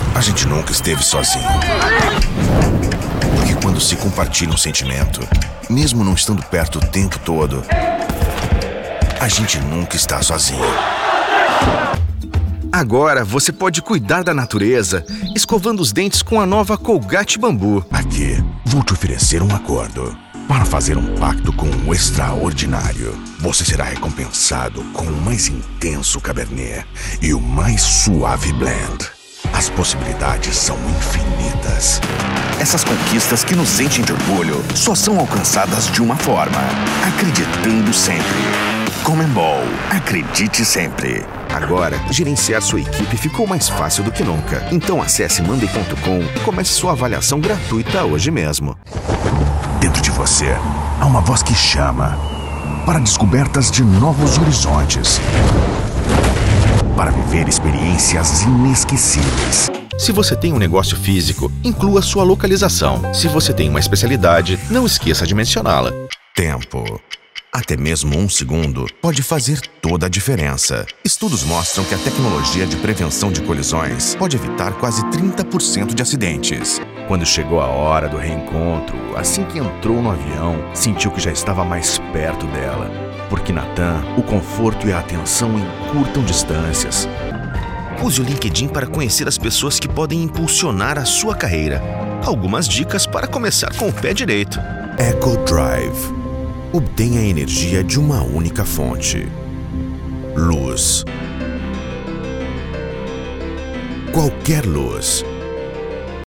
Male
Portuguese (Brazilian)
Yng Adult (18-29), Adult (30-50)
Neutral accent.
Television Spots
Explainer Videos
Male Voice Over Talent